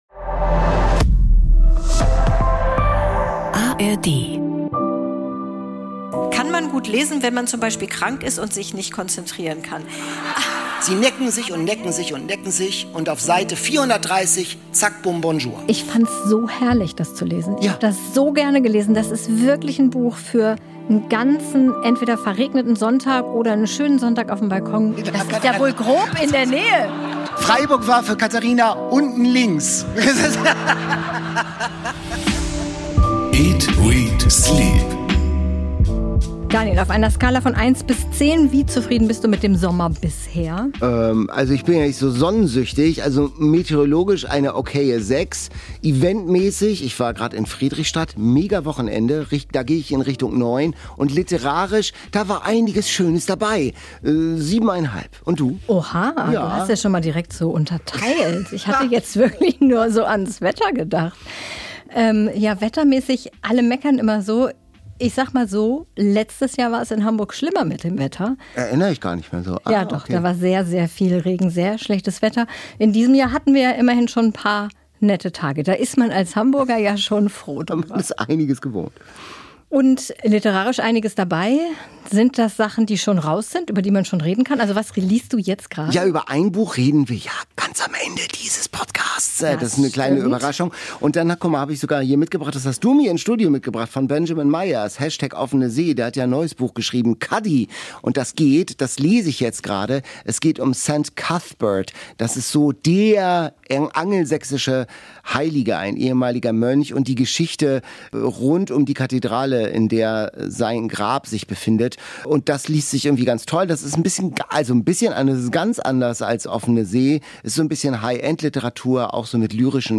Und mit Ingrid Noll sind die beiden Hosts durch Weinheim spaziert: Das Interview mit der Grande Dame der deutschen Kriminalliteratur fand im Rahmen einer Podcast-Session statt.